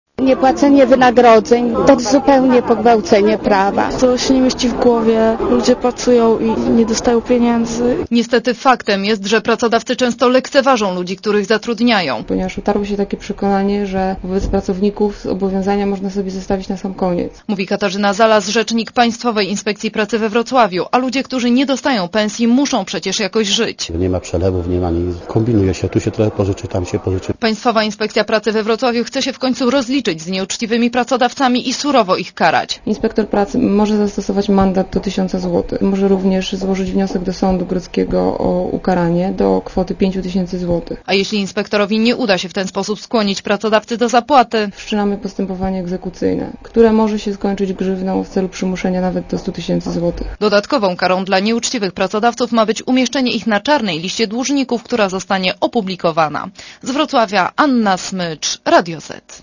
Źródło zdjęć: © PAP 05.10.2004 15:51 ZAPISZ UDOSTĘPNIJ SKOMENTUJ Relacja reportera Radia ZET Na uregulowanie ewentualnych zaległości nieuczciwi pracodawcy mają czas do 20. października.